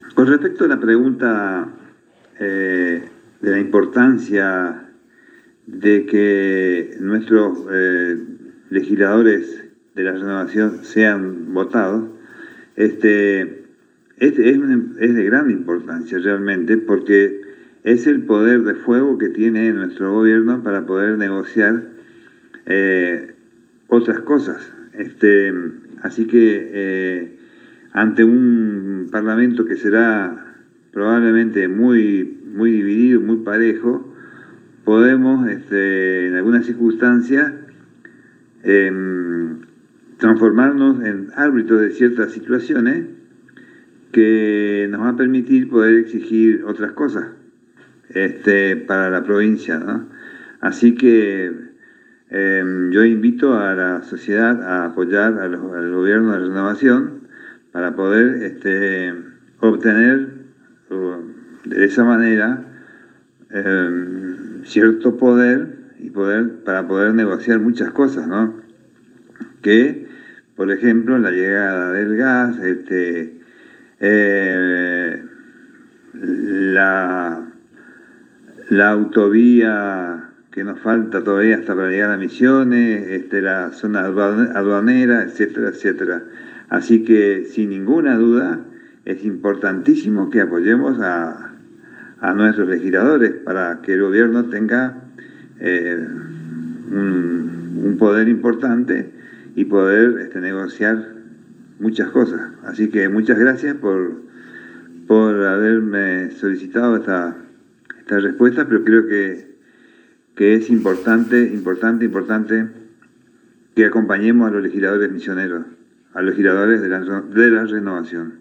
En diálogo exclusivo con la ANG, el galeno manifestó al ser consultado sobre la importancia de las próximas elecciones, que es muy importante que la ciudadanía de Misiones vote a los candidatos a Legisladores Nacionales del Frente Renovador porque es el poder que los misioneros tenemos para defender nuestros intereses.